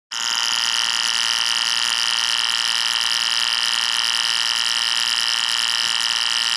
Hello, I'm using nrf52840 DK to test 1khz tone.
output_1khz_distortion.wav